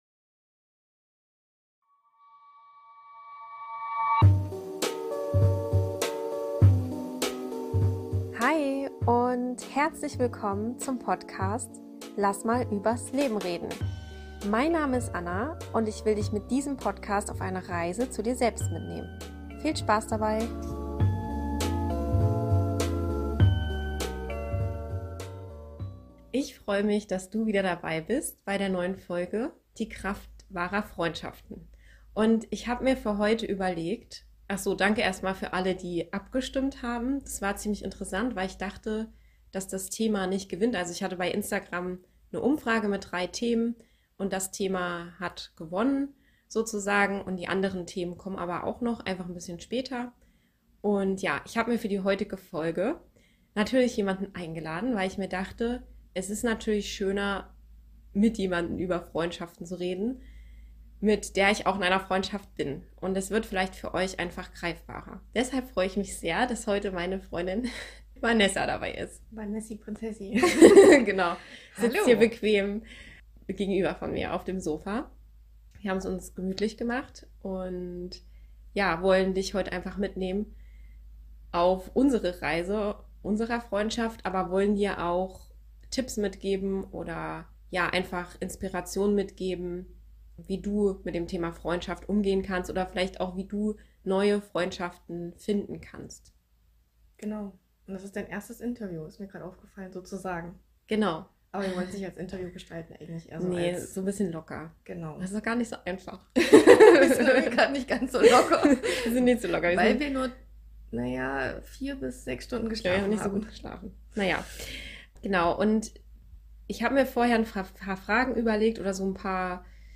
Für diese Folge habe ich mir eine Interviewpartnerin eingeladen und freue mich sehr, sie endlich zu veröffentlichen.